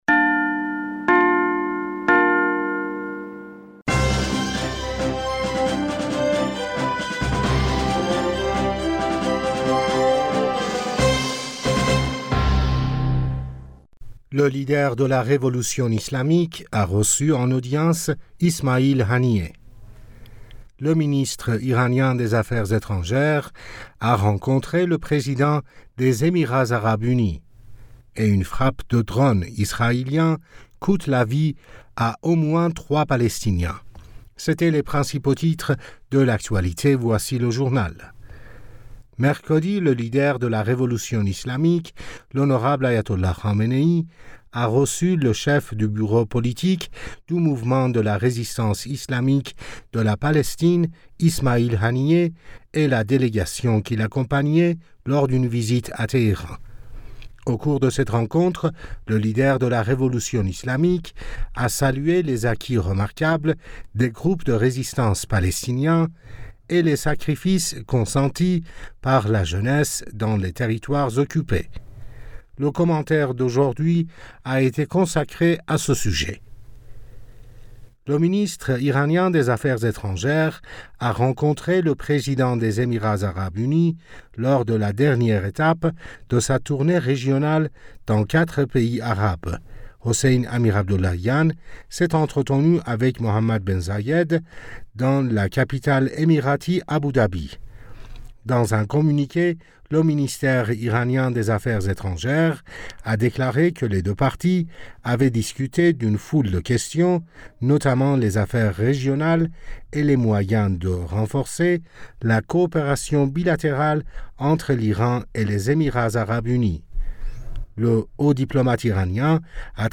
Bulletin d'information du 22 Juin 2023